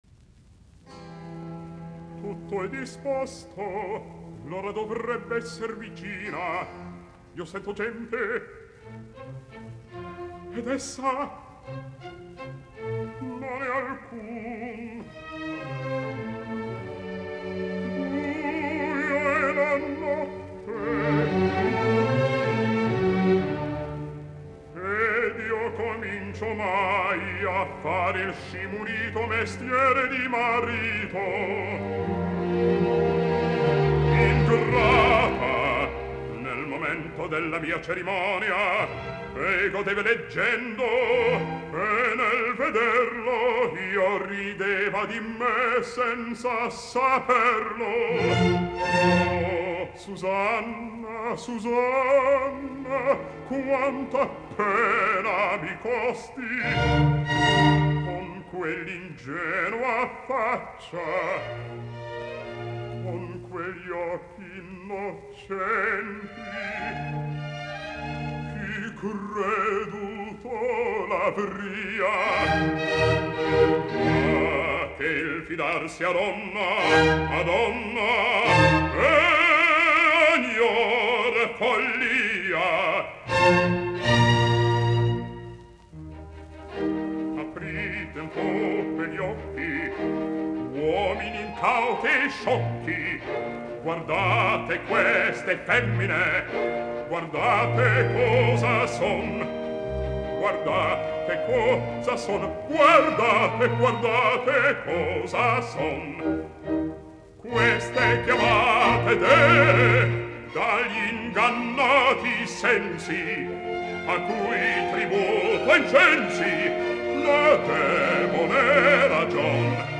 musiikkiäänite
basso